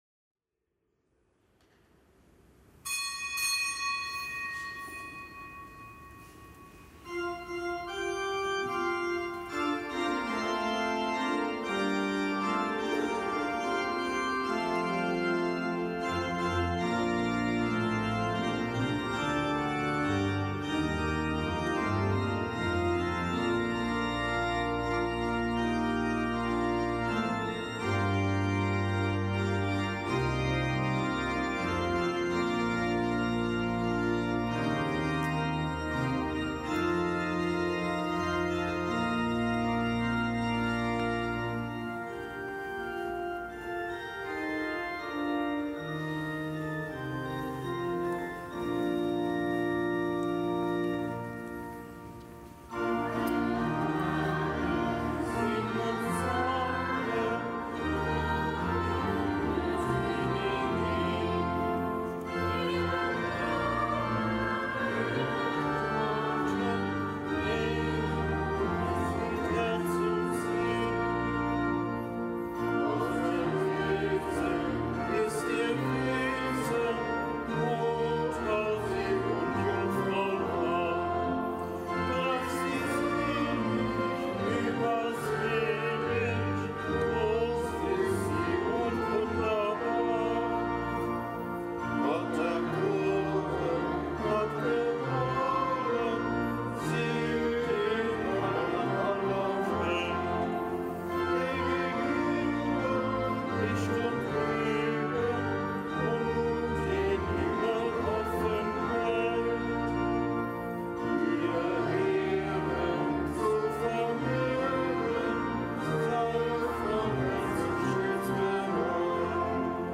Kapitelsmesse aus dem Kölner Dom am Dienstag der achtzehnten Woche im Jahreskreis. Nichtgebotener Gedenktag des Weihetags der Basilika Santa Maria Maggiore in Rom.